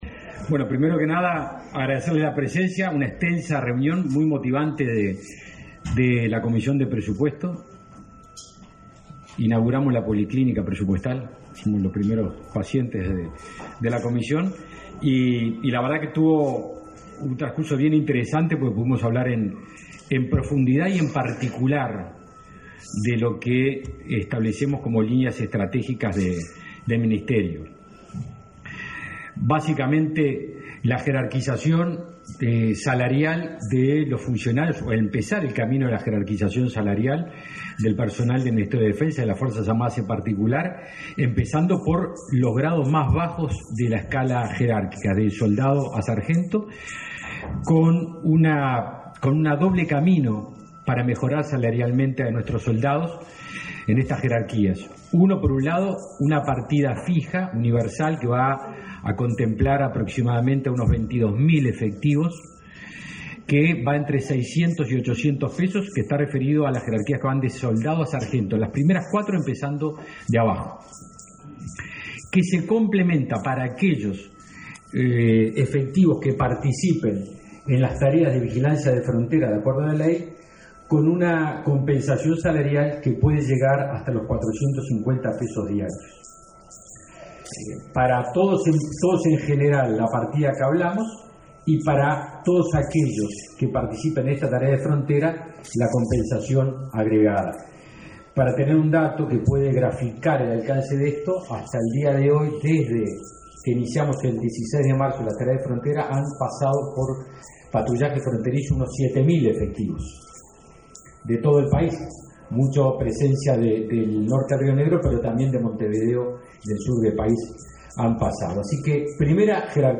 Aumento salarial para el personal de los grados más bajos, compensaciones para los efectivos que realizan tareas de patrullaje fronterizo, modernización de equipamientos y plataformas, racionalización del número de oficiales superiores y cambios en la ley orgánica de las Fuerzas Armadas son las líneas estratégicas prioritarias del Ministerio de Defensa, informó en rueda de prensa el titular de la cartera, Javier García.